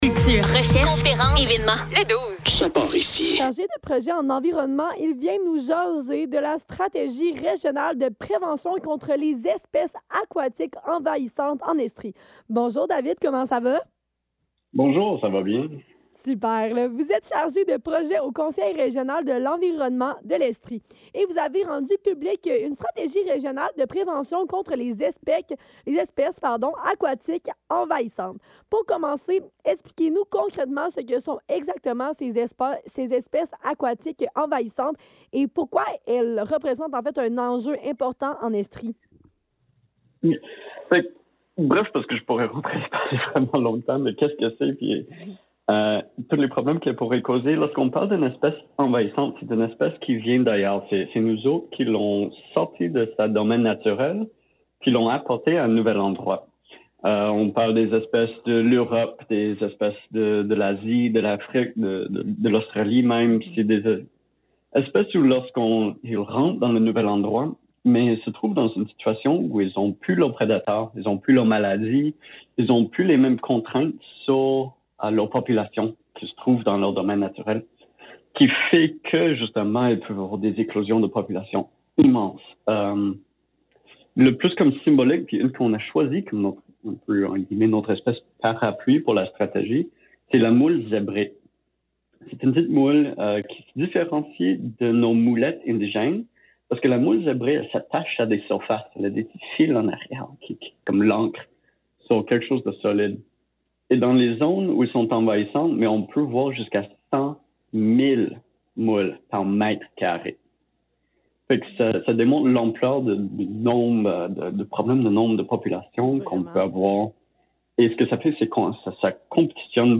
Le Douze - Entrevue dans le cadre de la Stratégie régionale de prévention contre les espèces aquatiques envahissantes en Estrie - 9 février 2026